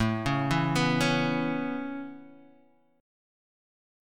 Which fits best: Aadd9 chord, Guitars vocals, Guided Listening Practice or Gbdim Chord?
Aadd9 chord